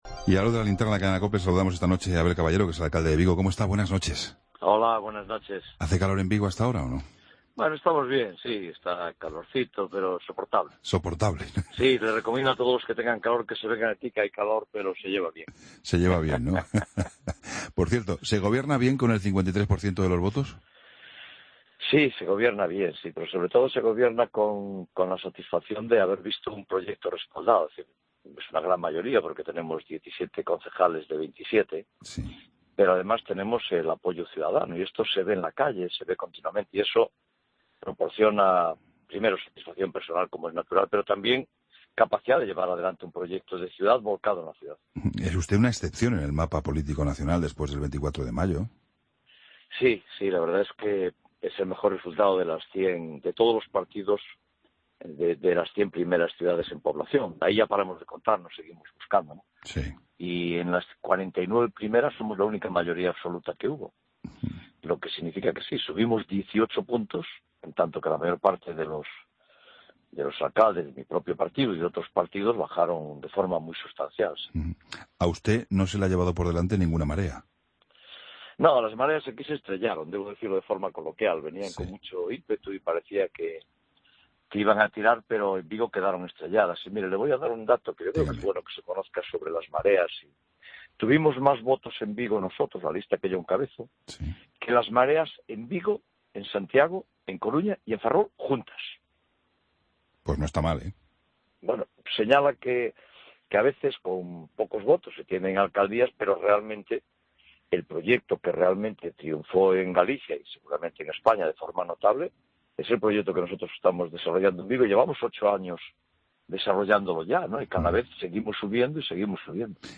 Escucha la entrevista a Abel Caballero, alcalde de Vigo, en La Linterna